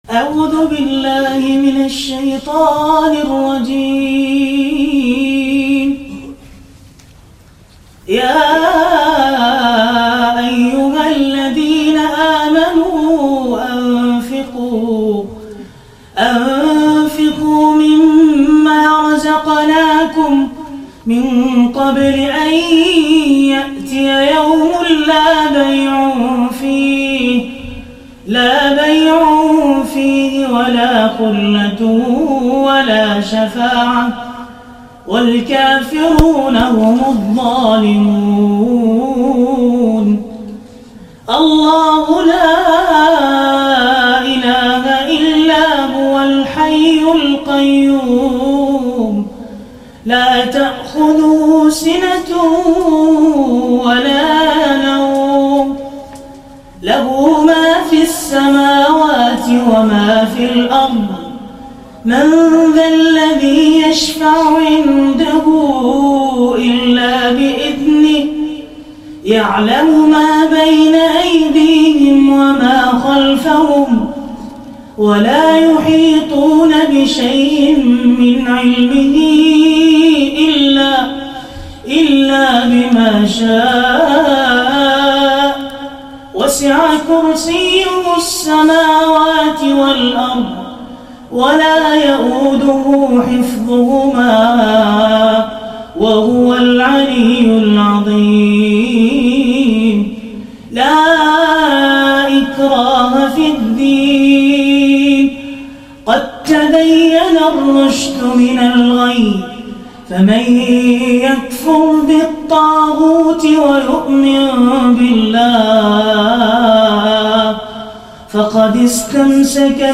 Beautiful Qur’an Recitation HD